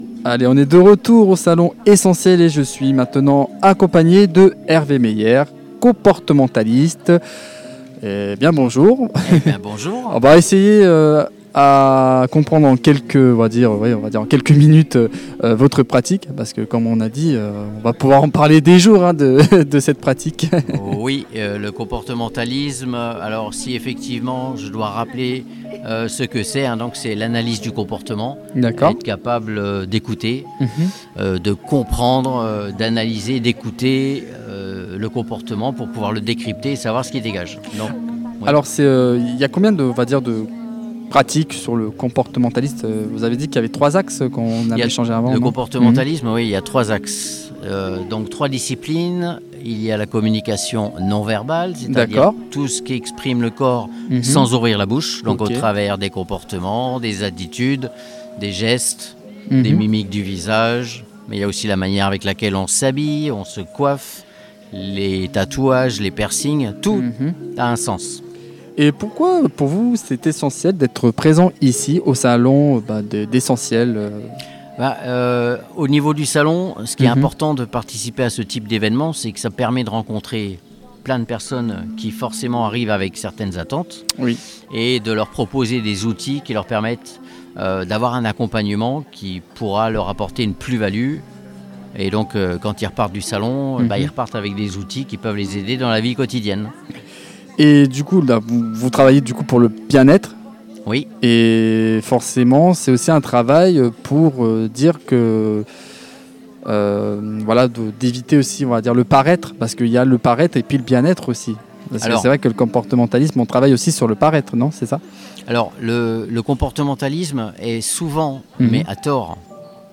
Les interviews du Salon Essenti’Elles – Édition 2026
À l’occasion du Salon Essenti’Elles, organisé les 7 et 8 mars 2026 au gymnase de Châtillon-Coligny, l’équipe de Studio 45 est allée à la rencontre des organisatrices et des nombreux exposants présents durant ce week-end consacré au bien-être et à l’univers féminin.